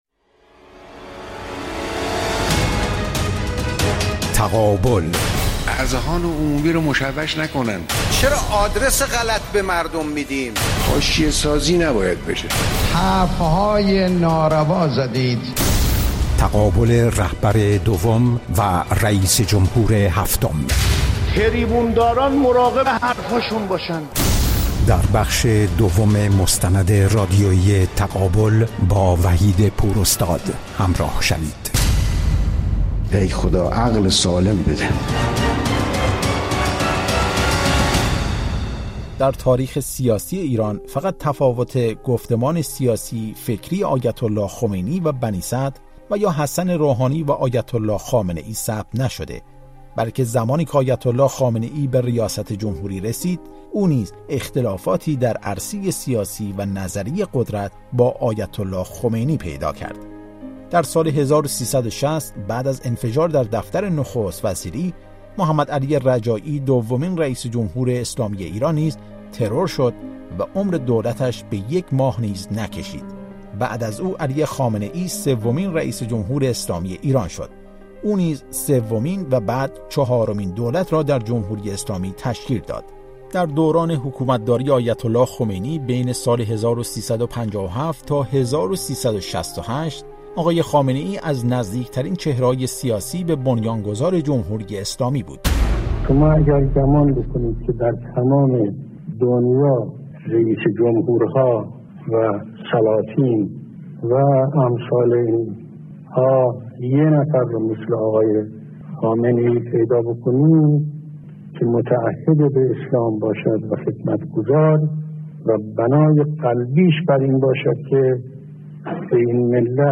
مستند رادیویی تقابل/ بخش دوم